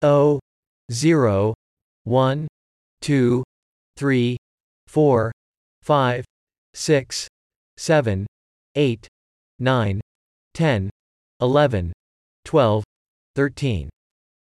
Voici un MP3 à écouter de la bonne façon de prononcer 0 – 13 en anglais.